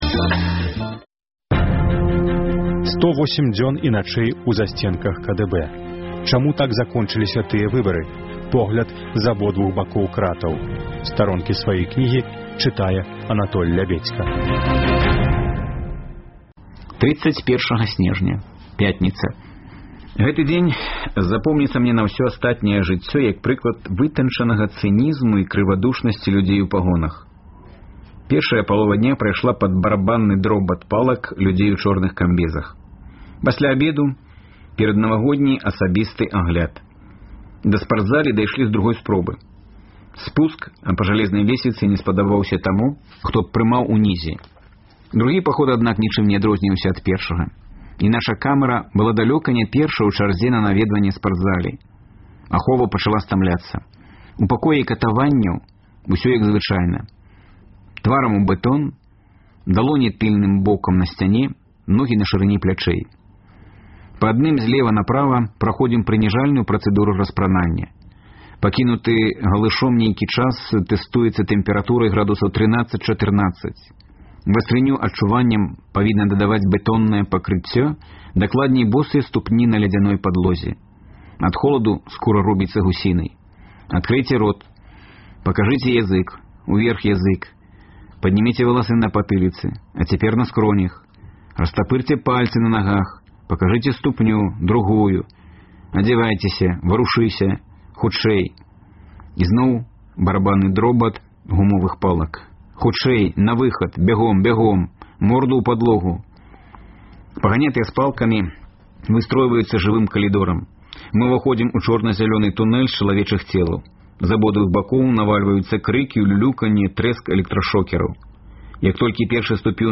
На хвалях Радыё Свабода гучаць разьдзелы кнігі Анатоля Лябедзькі «108 дзён і начэй у засьценках КДБ» у аўтарскім чытаньні.